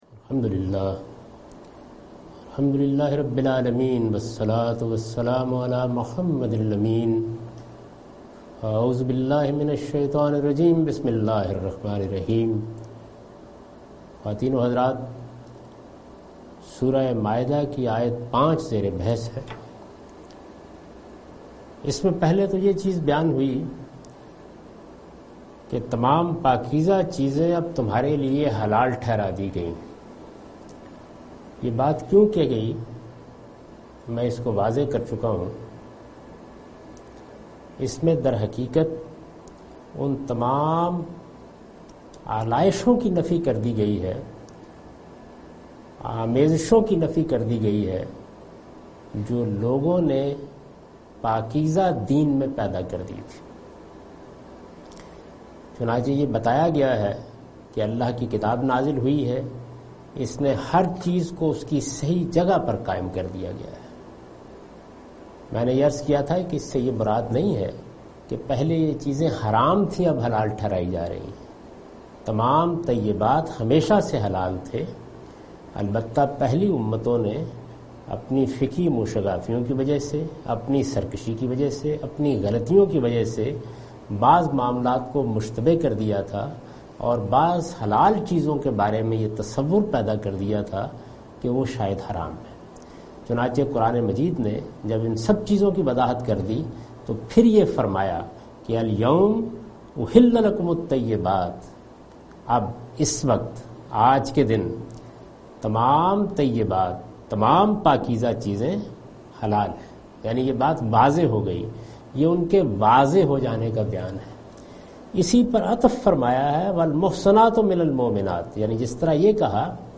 Surah Al-Maidah - A lecture of Tafseer-ul-Quran, Al-Bayan by Javed Ahmad Ghamidi.